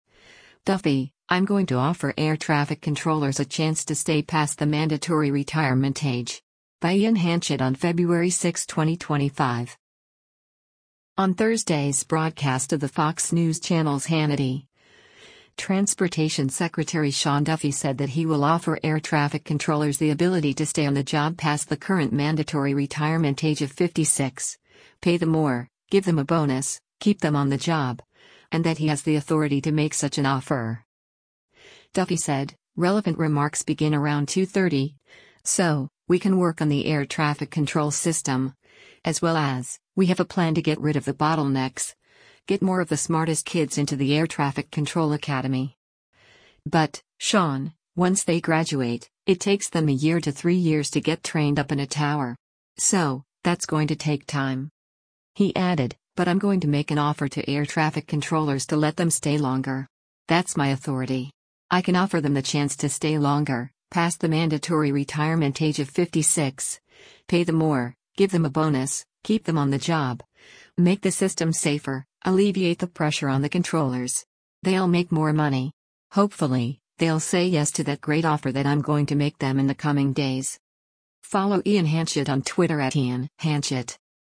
On Thursday’s broadcast of the Fox News Channel’s “Hannity,” Transportation Secretary Sean Duffy said that he will offer air traffic controllers the ability to stay on the job past the current mandatory retirement age of 56, “pay them more, give them a bonus, keep them on the job,” and that he has the authority to make such an offer.